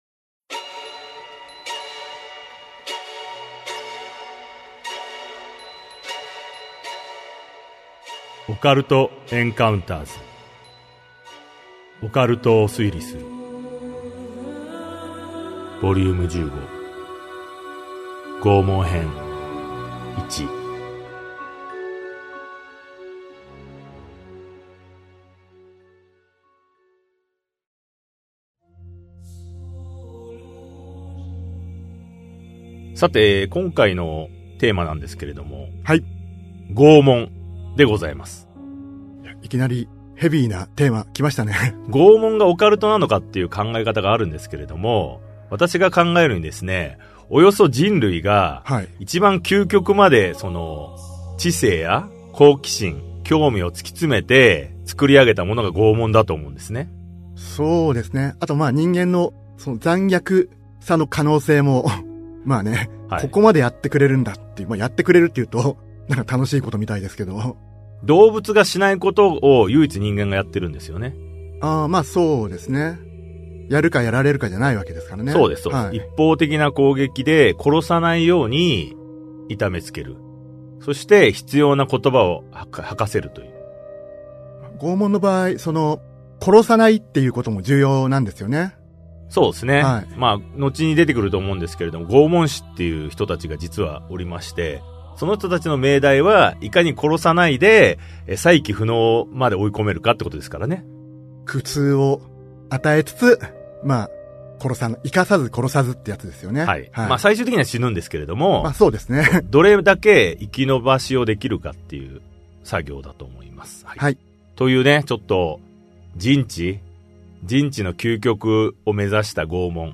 [オーディオブック] オカルト・エンカウンターズ オカルトを推理する Vol.15 拷問編1